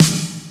Waka SNARE ROLL PATTERN (74).wav